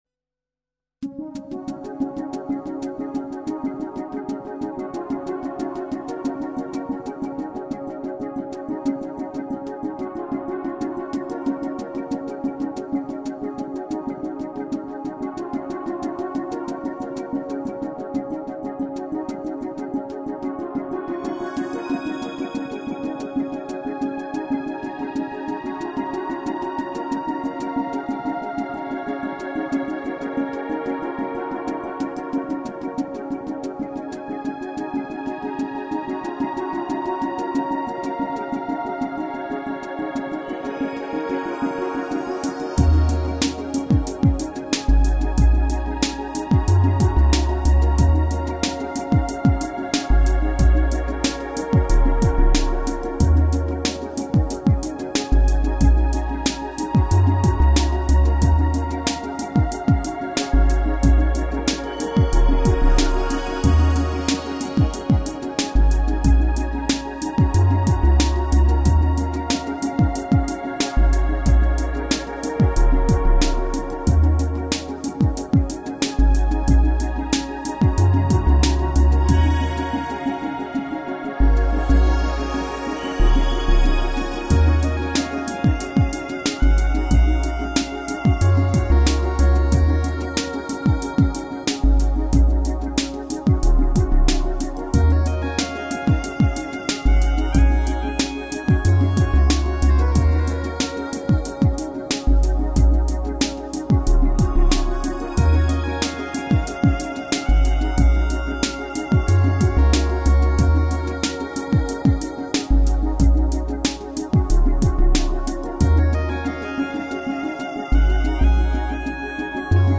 Drum & bass
Ambient